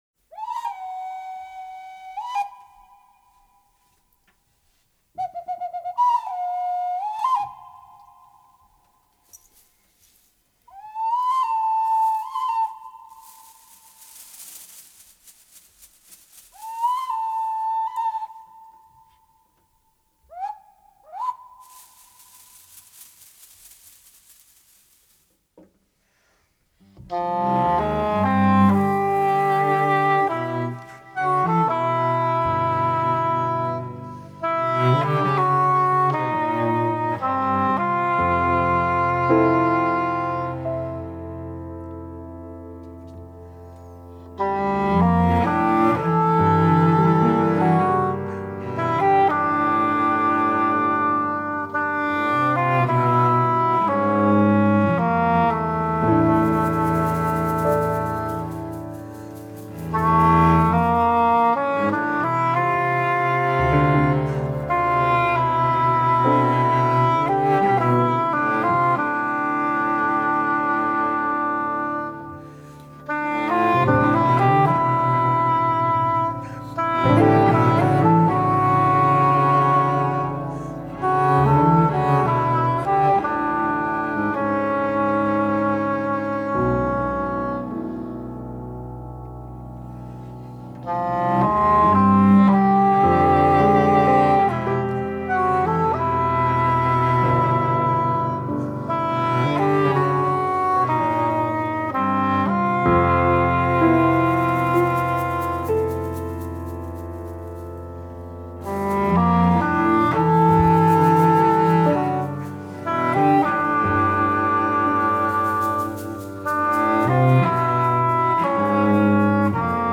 alt hobo
cello
vleugel
percussionist